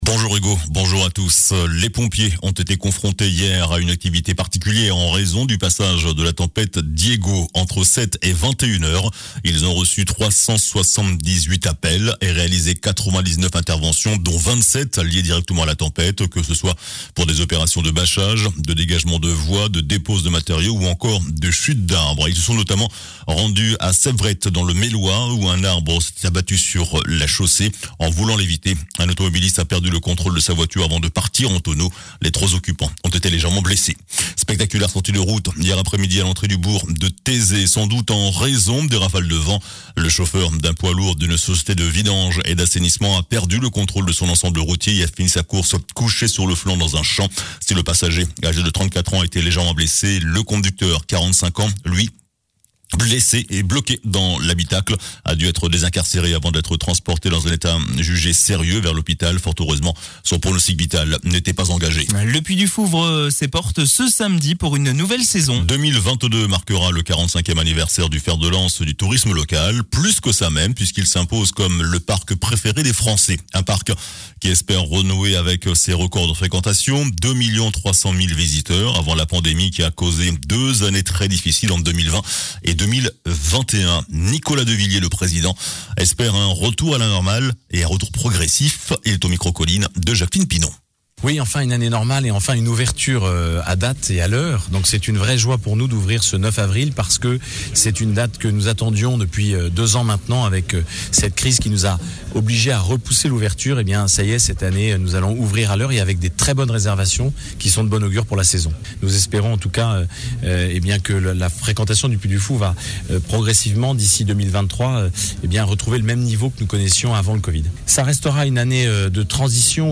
JOURNAL DU SAMEDI 09 AVRIL